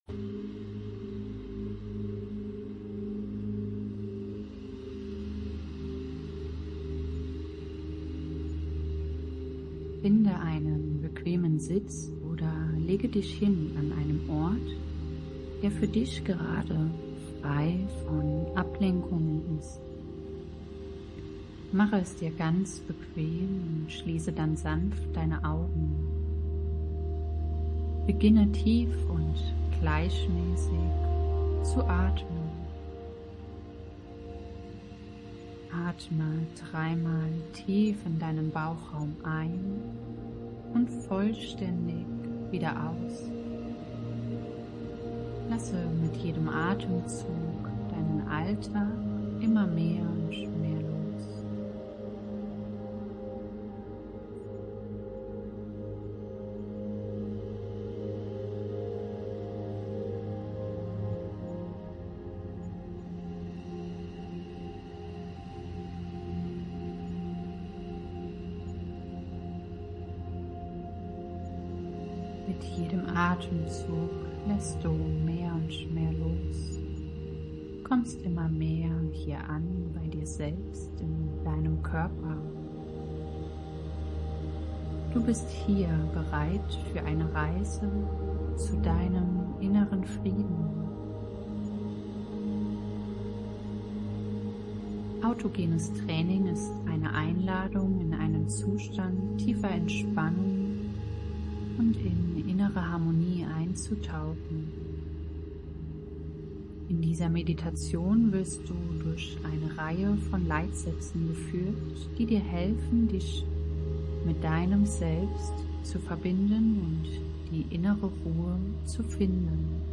Erlebe eine geführte 27-minütige Meditation, die auf den Leitsätzen des autogenen Trainings basiert.